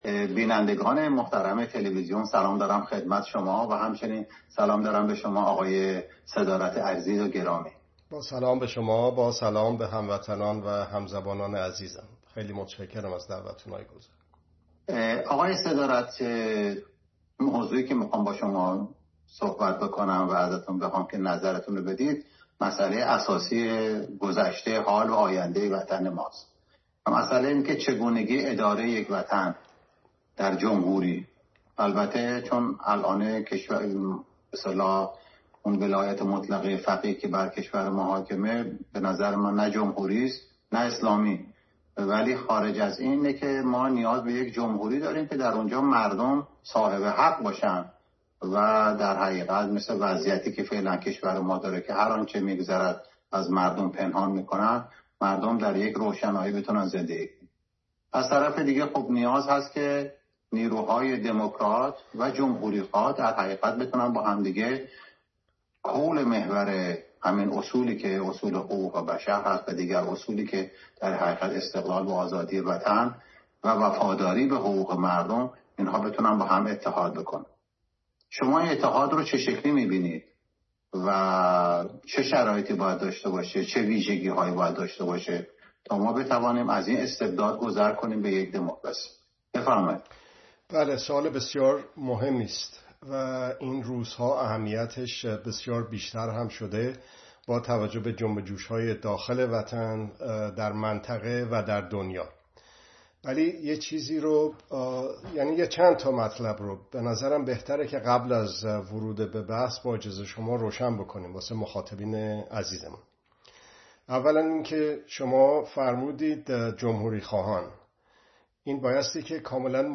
در مصاحبه